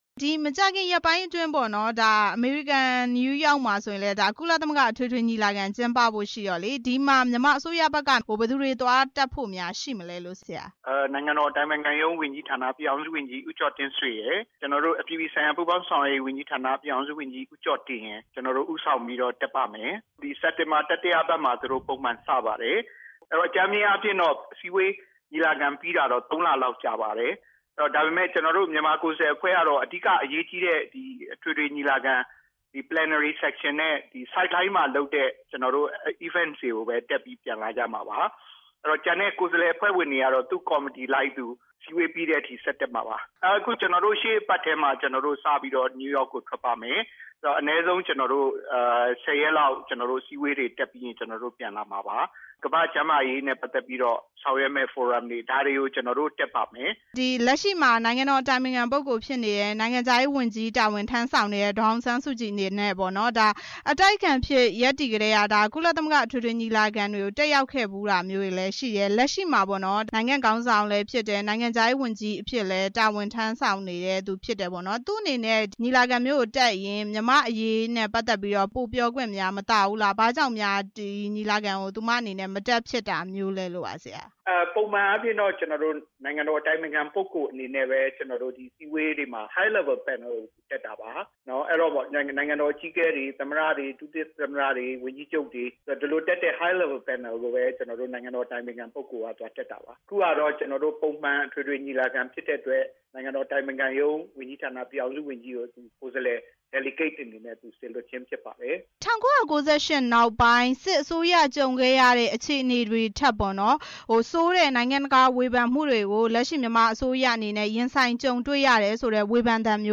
နိုင်ငံခြားရေးဝန်ကြီးဌာန အမြဲတမ်းအတွင်းဝန်နဲ့ မေးမြန်းချက်